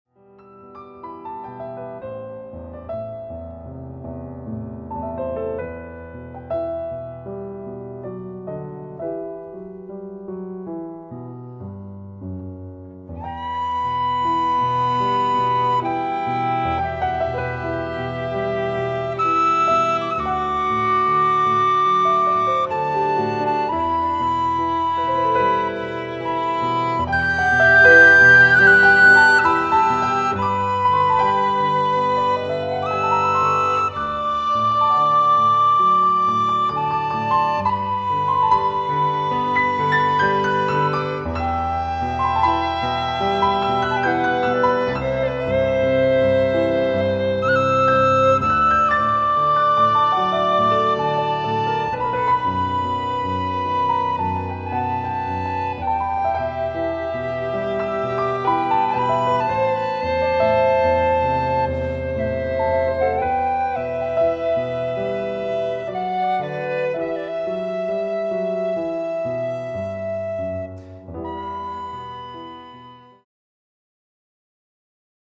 Violine, Tinwistle, Klavier